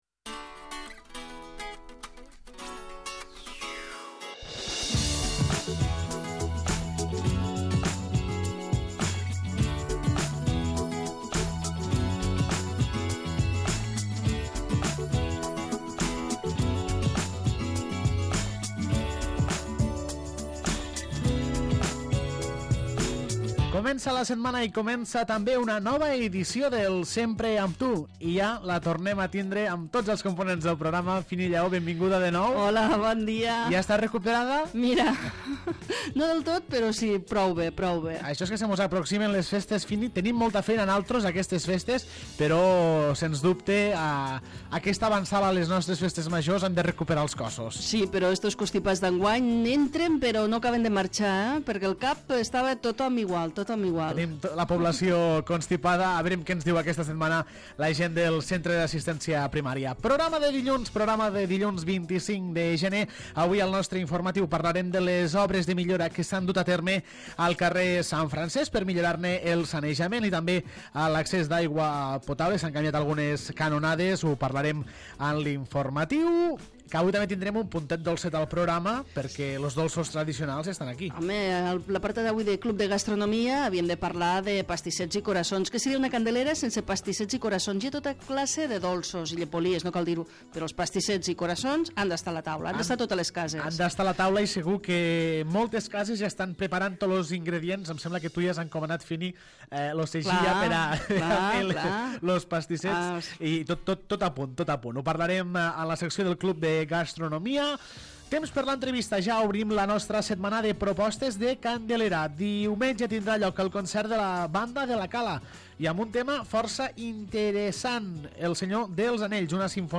al temps de l'entrevista